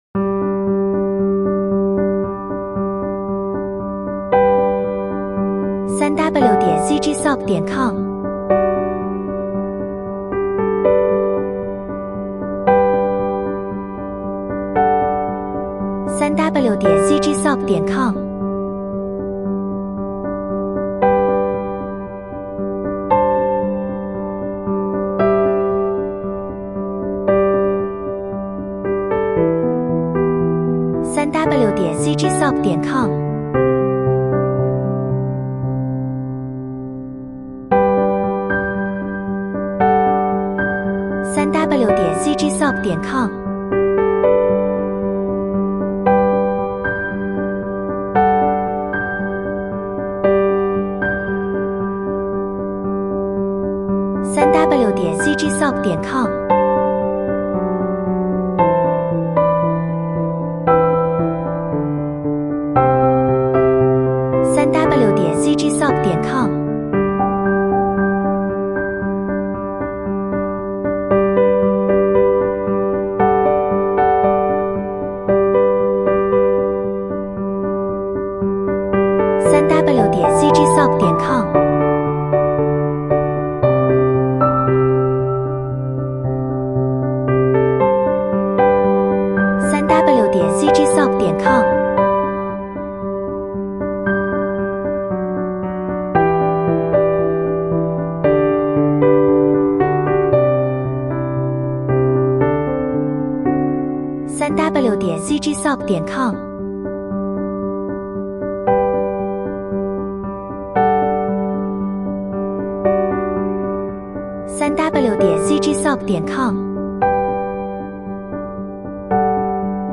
romantic
背景音乐